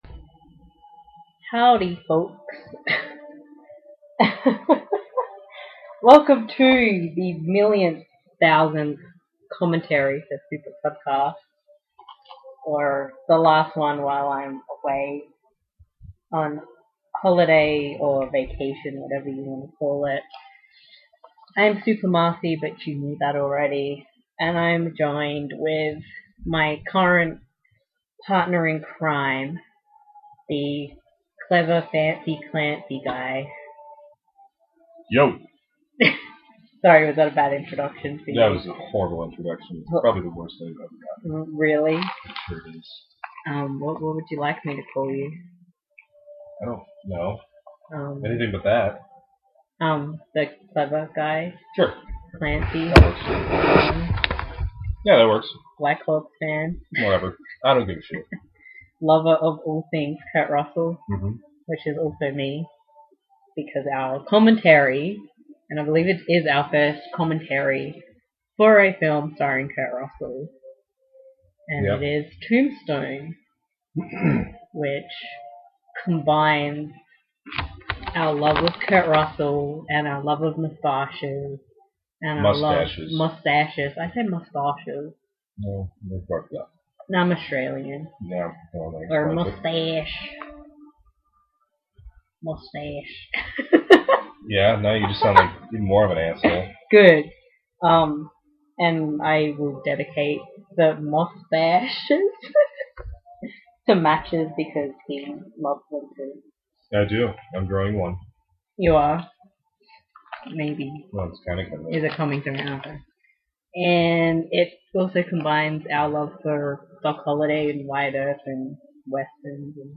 You simply need to grab a copy of the film, and sync up the podcast audio with the film.